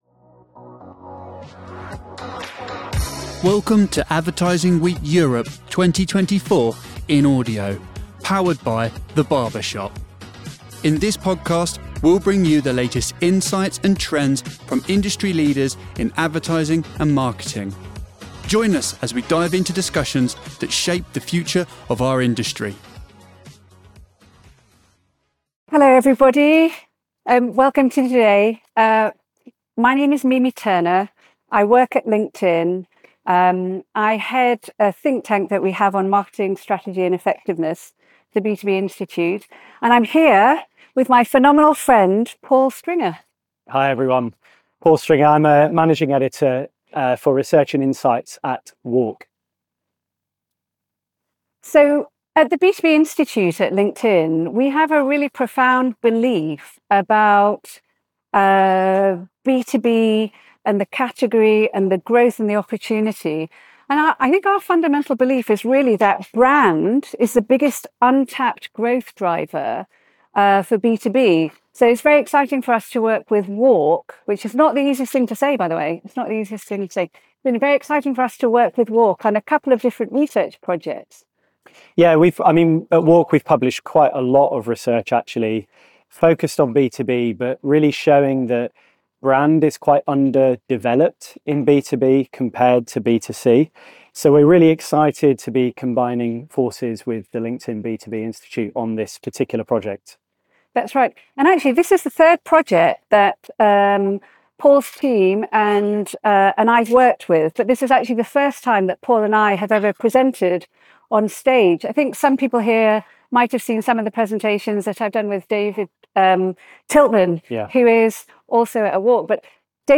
LinkedIn & WARC: Unlocking the Multi-Billion Promise of B2B - Advertising Week Europe 2024 in Audio